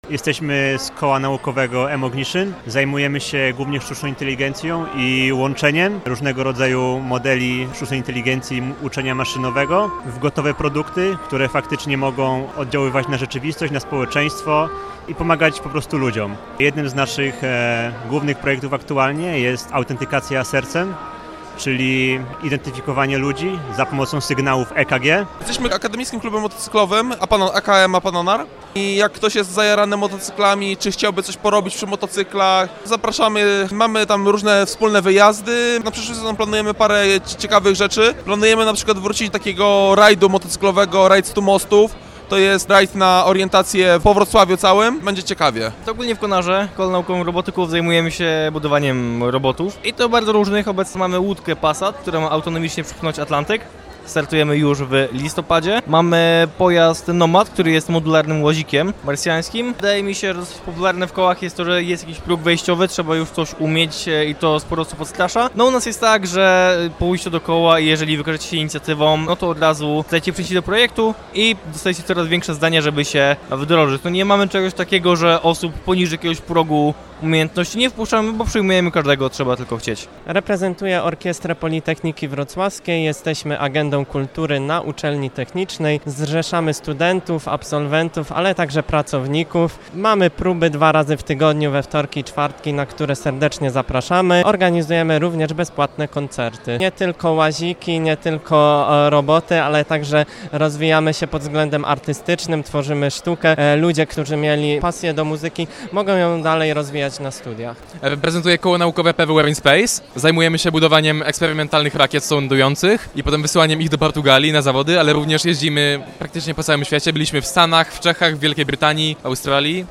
O tym, jakie projekty realizują koła, opowiedzieli ich przedstawiciele